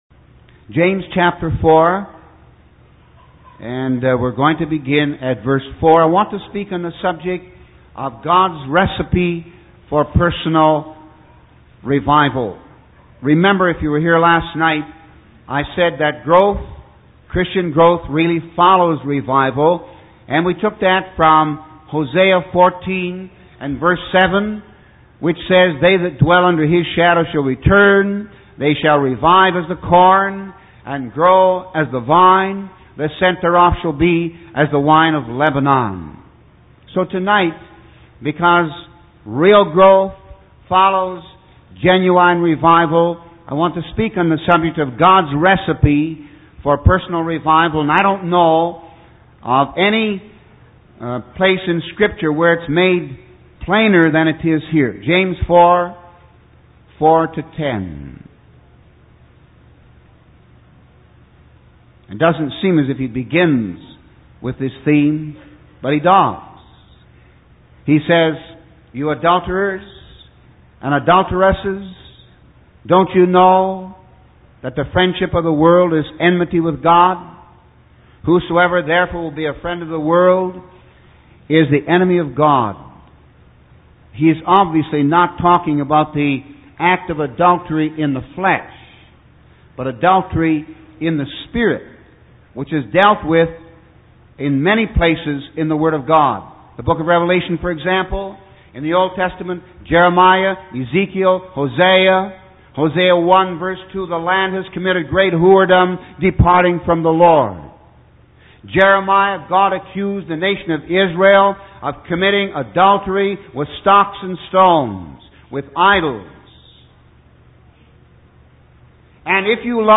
In this sermon, the preacher discusses the concept of personal revival and its connection to Christian growth. He emphasizes that genuine revival leads to real growth, using Hosea 14:7 as a reference.